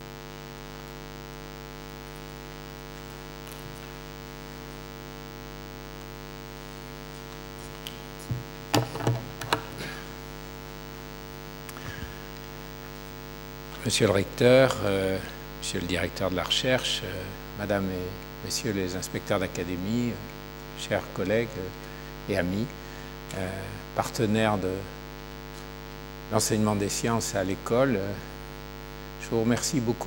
fichier avec bruit wav 5,8 Mo (nouvelle fenêtre)